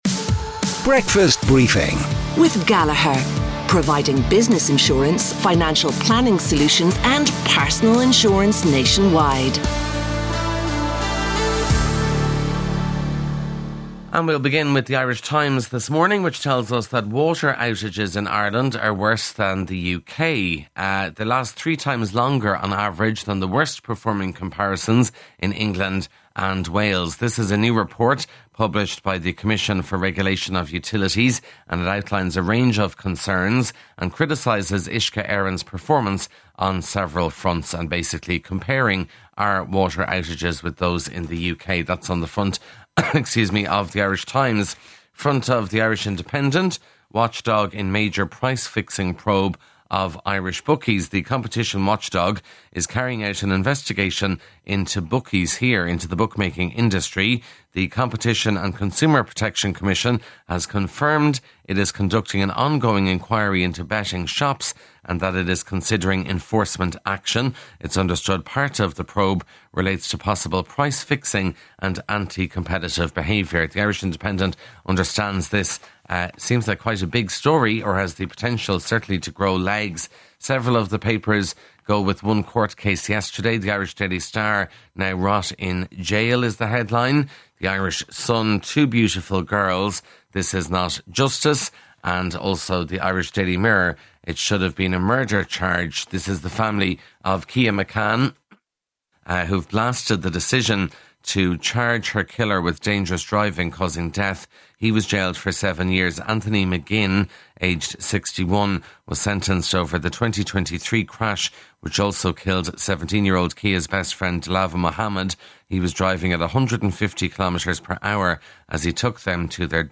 breakfast_briefing_newspaper_rev_bb1c7b68_normal.mp3